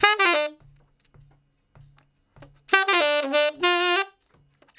066 Ten Sax Straight (D) 01.wav